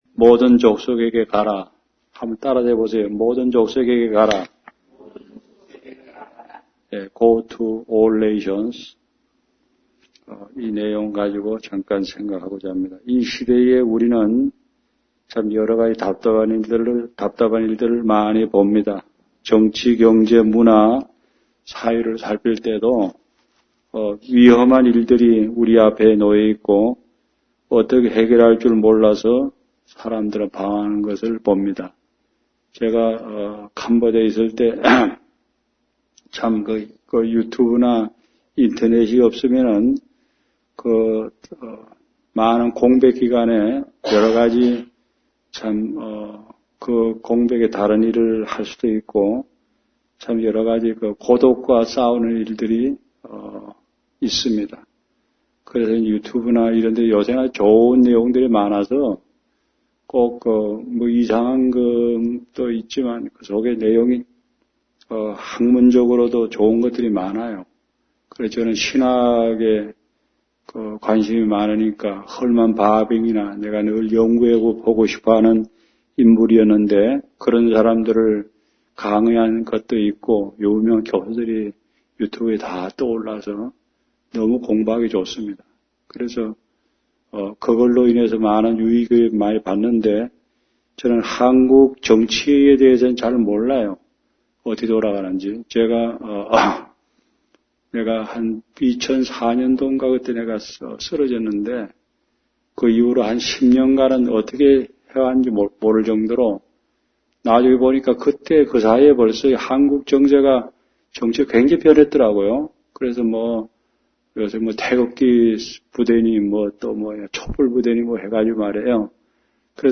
송구영신예배